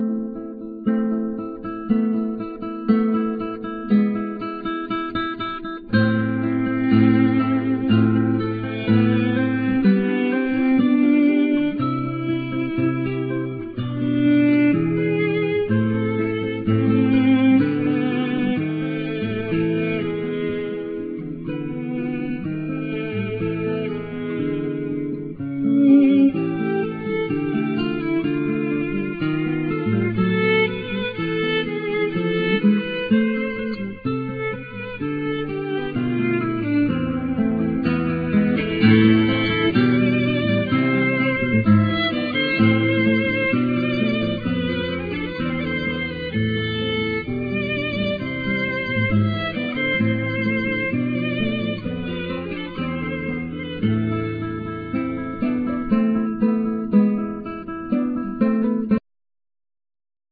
Voice,Keyboards,Dong ruan,Samplers,Percussions
Classic guitar
Trumpet,Piccolo trumpet
Timpanos,Snare,Drum,Cymbals,Gong
Chinese voice